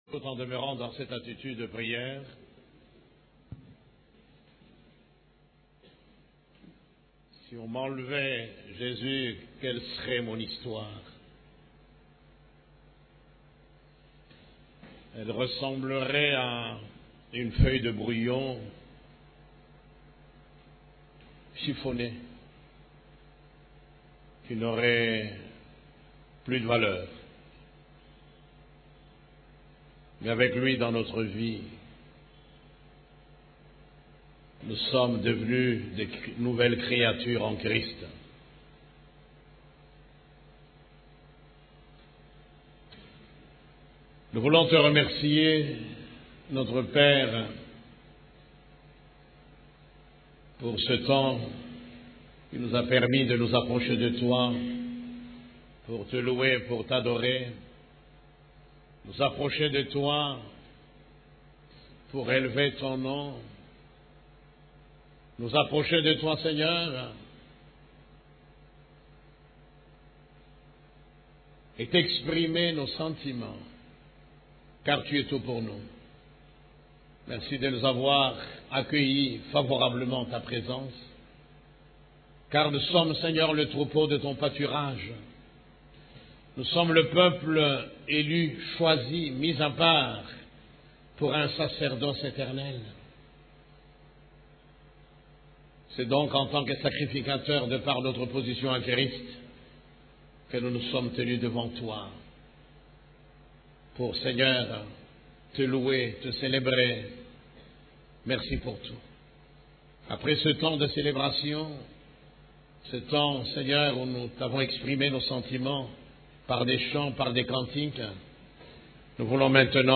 CEF la Borne, Culte du Dimanche, Comment faire face à l'adversité